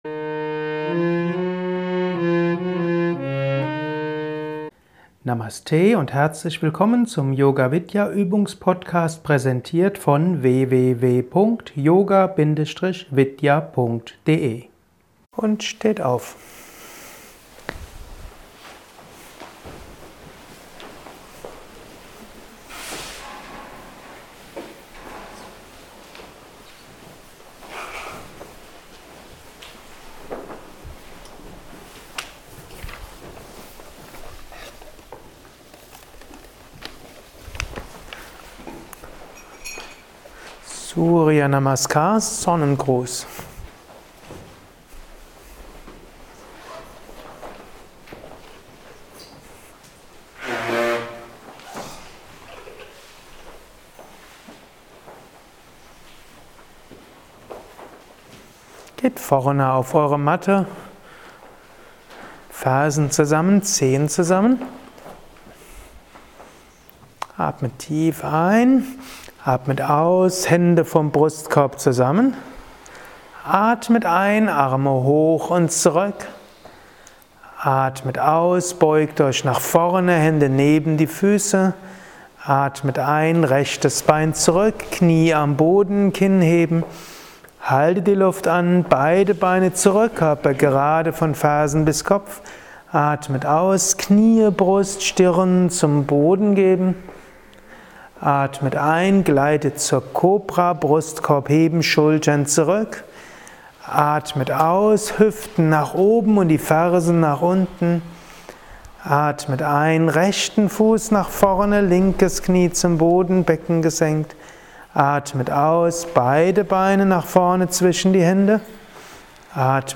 Eine 15-minütige Übungsfolge für neue Kraft und Energie: Surya Namaskar Sonnengruß mit genaueren Erläuterungen, mit den Surya Mantras sowie den Surya Bija Mantras. Verschiedene Vira Bhadrasana Heldenstellung Variationen.